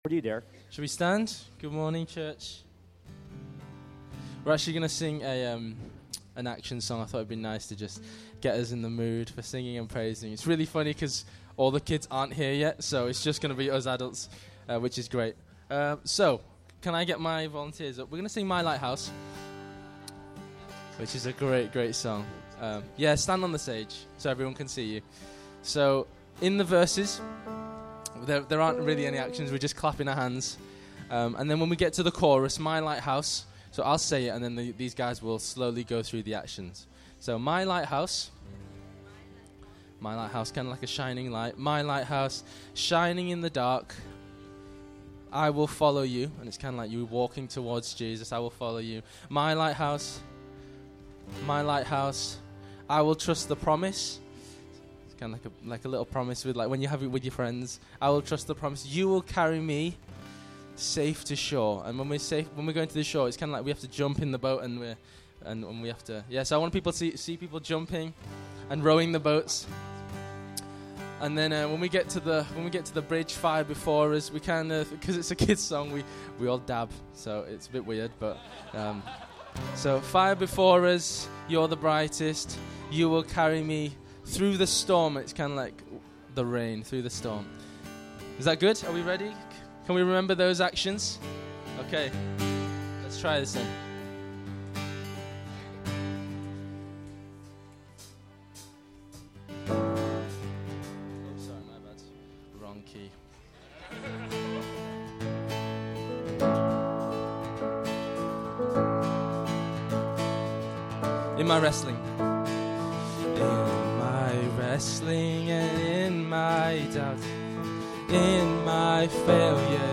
Worship August 5, 2018